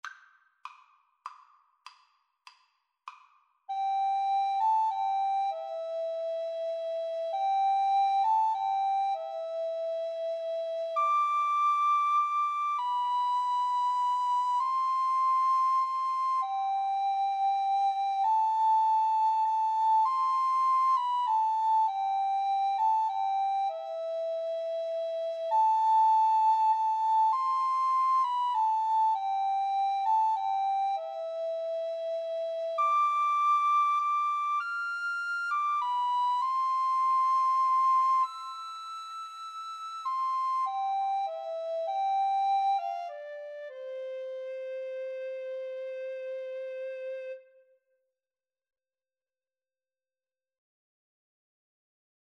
is a popular Christmas carol
6/8 (View more 6/8 Music)